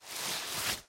Здесь вы найдете как знакомые «хрустящие» эффекты корзины, так и более современные варианты.
Звук удаления или перемещения файла в корзину